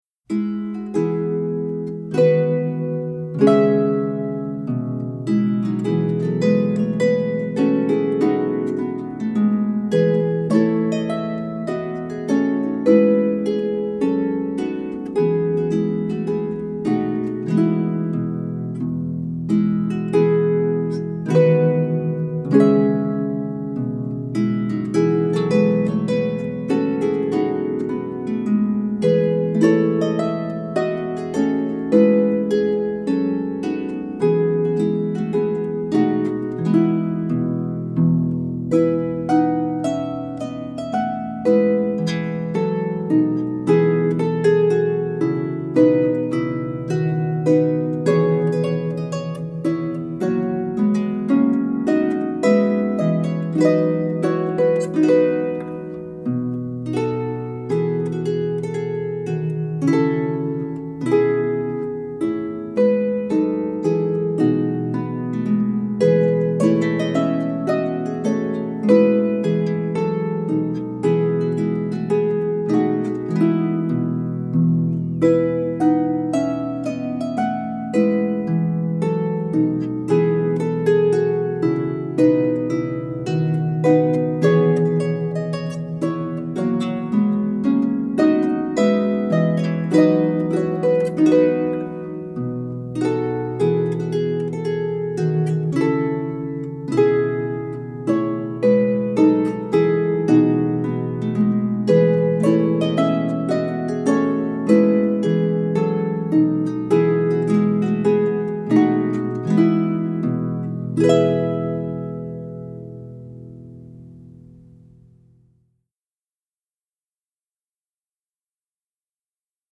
for solo lever or pedal harp